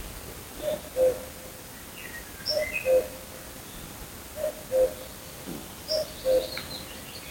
Common Cuckoo, Cuculus canorus
Administratīvā teritorijaNīcas novads
StatusSinging male in breeding season